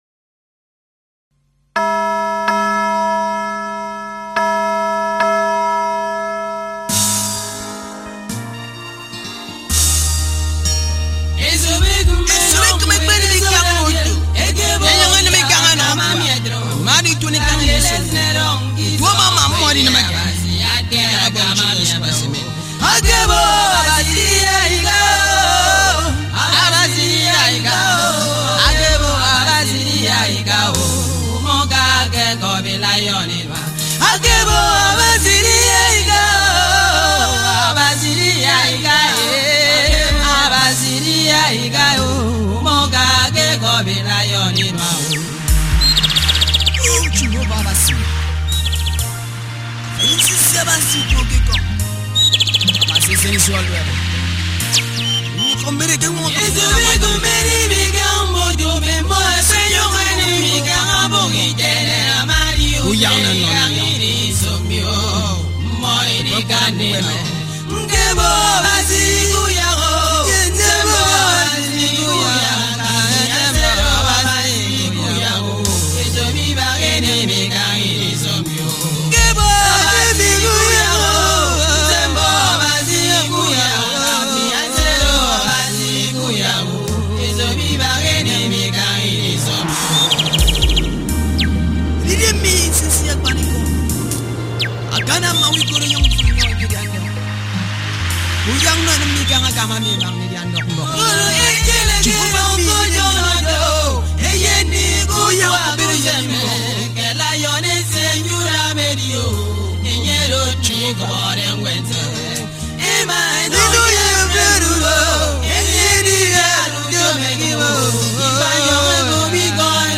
February 28, 2025 Publisher 01 Gospel 0
Nigerian gospel